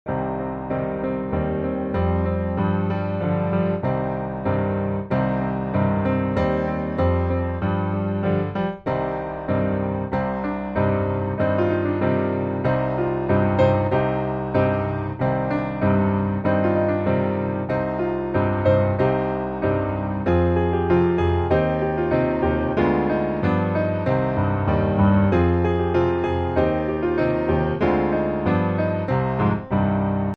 Traditional Congo melody
C Mineur